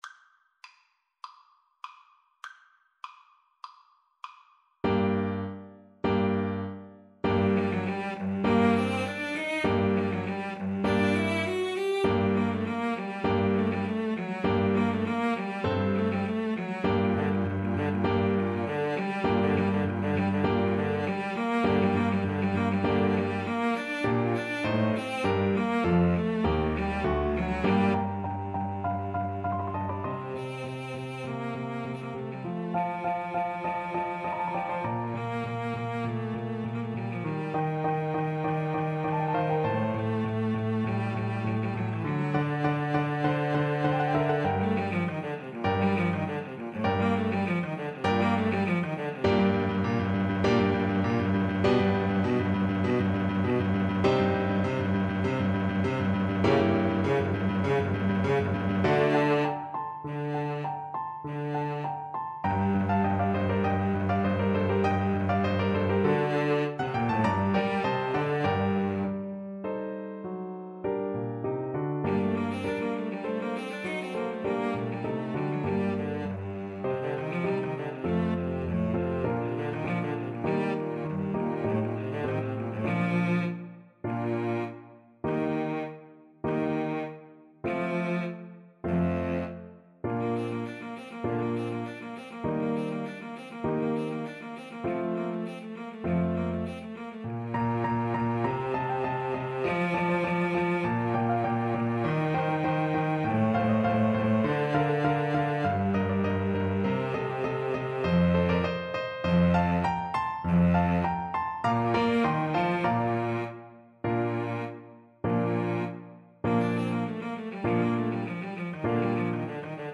4/4 (View more 4/4 Music)
Allegro (View more music marked Allegro)
Cello Duet  (View more Advanced Cello Duet Music)
Classical (View more Classical Cello Duet Music)